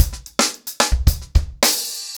TimeToRun-110BPM.39.wav